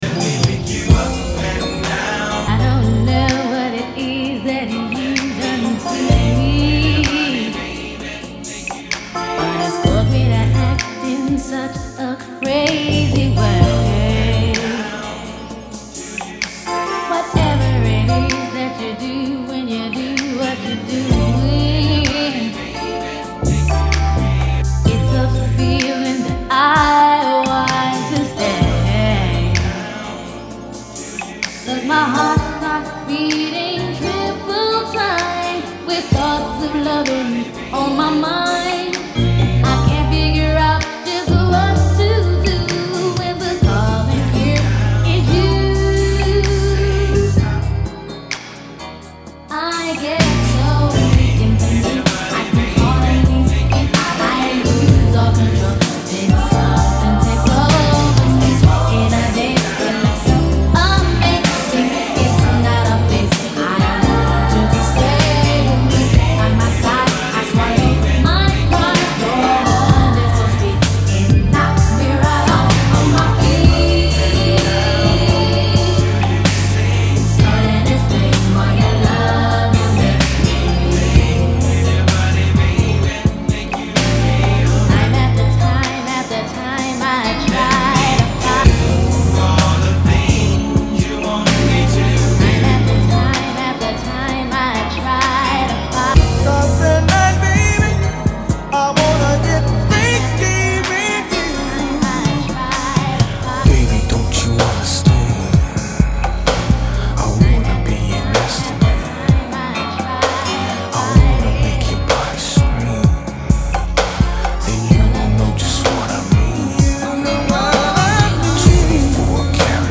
Old School RnB